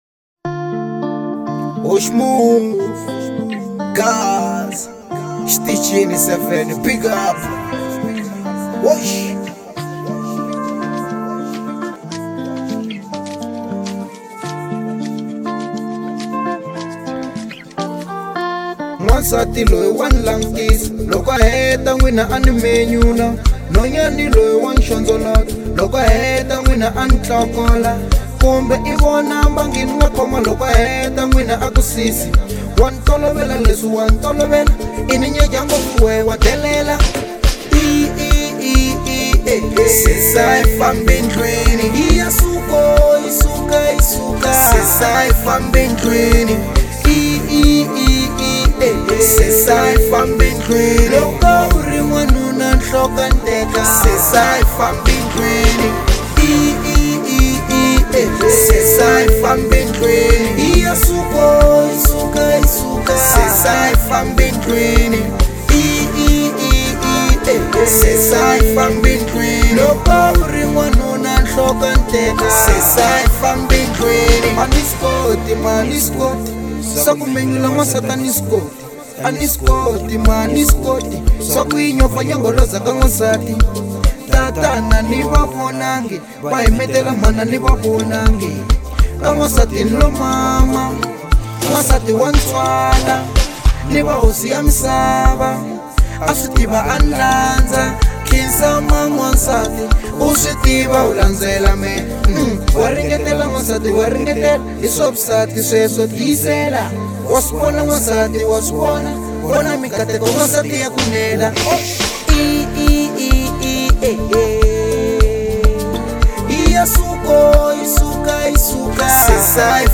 02:46 Genre : Afro Pop Size